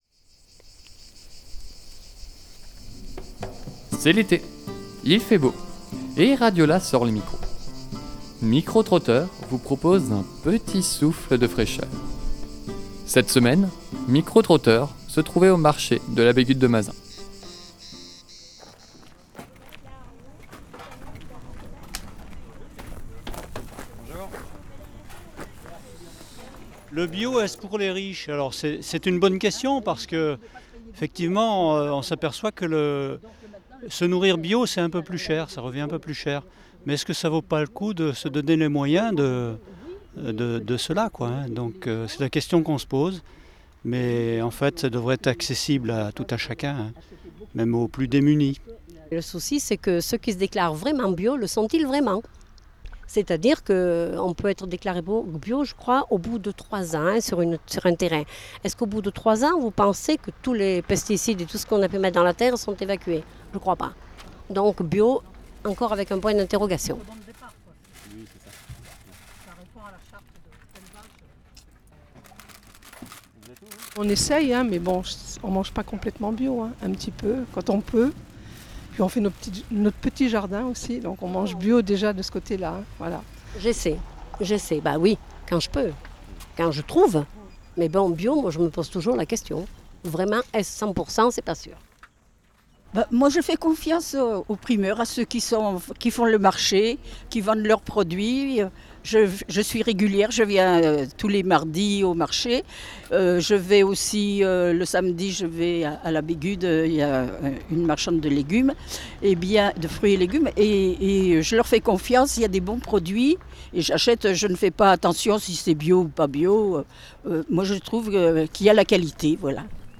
Cet été RadioLà sort les micros pour cette première émission de « Micro trotteur ».Nous nous sommes rendu au marché de la Bégude-de-Mazenc pour poser une question aux personnes sur place :